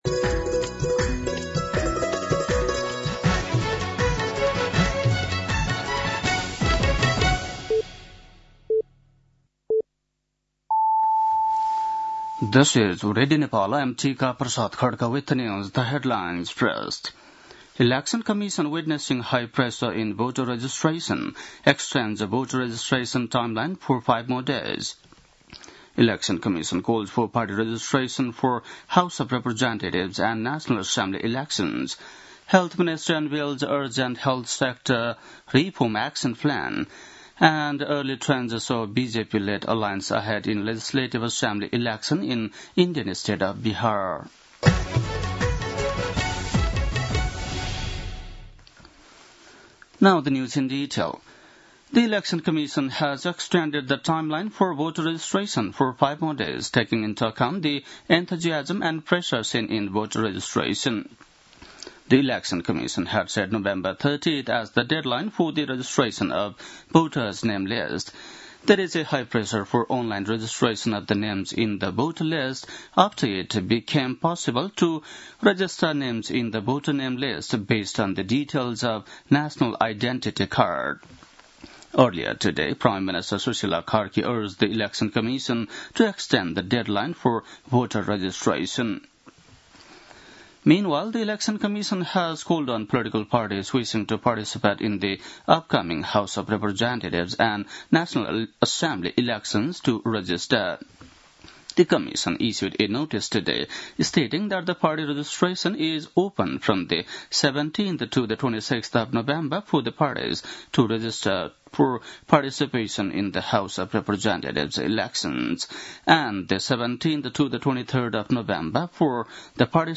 बेलुकी ८ बजेको अङ्ग्रेजी समाचार : २८ कार्तिक , २०८२